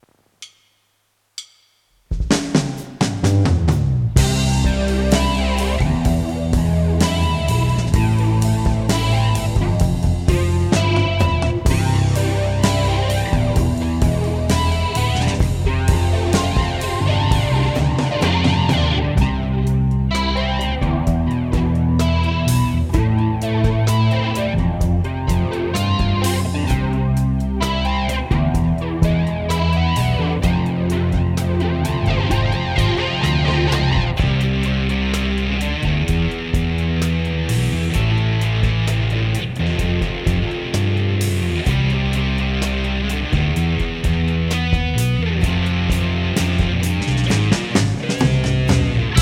Ретро стерео панорамирование.
вот как раз не так давно делал сведение в подобной стилистике. На барабасах посыл на Soundtoys Little Plate, после него эмуляция плёнки (на FX канале) Вложения LCR.mp3 LCR.mp3 1,9 MB · Просмотры: 873